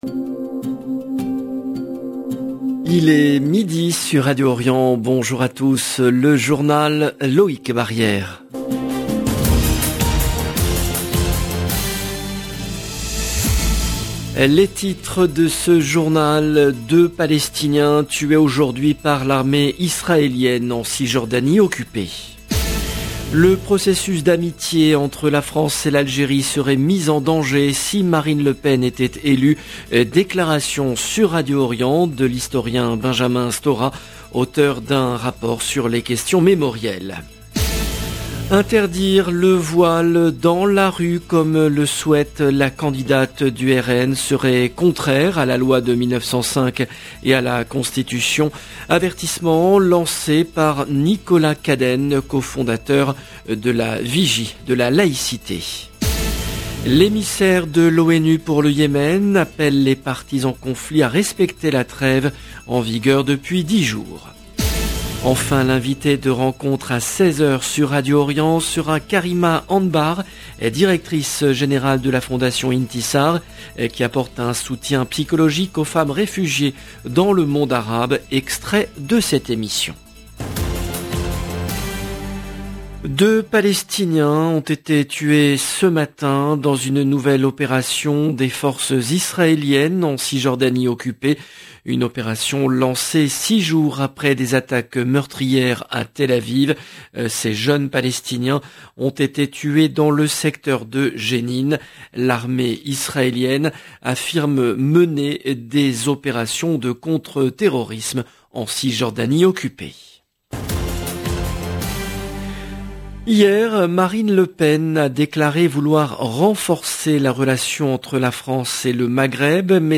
LB JOURNAL EN LANGUE FRANÇAISE
Déclaration sur Radio Orient de l’historien Benjamin Stora, auteur d’un rapport sur les questions mémorielles.